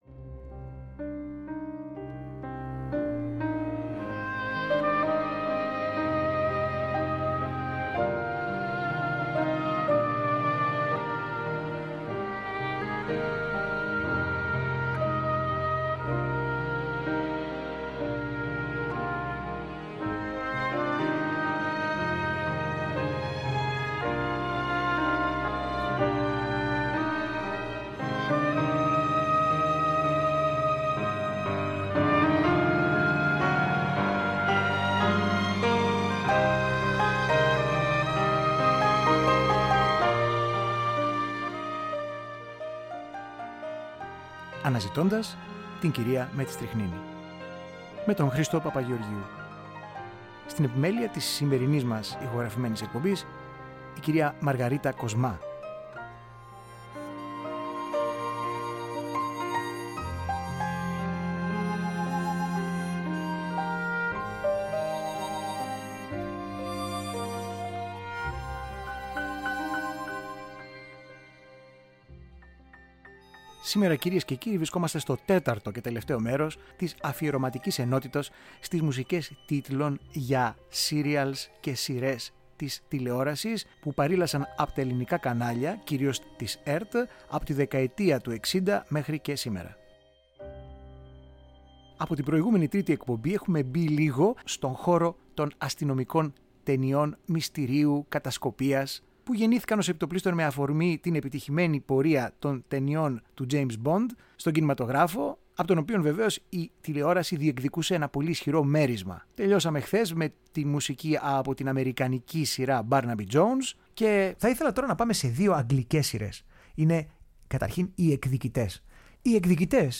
Μουσικη Τιτλων Τηλεοπτικων Σειρων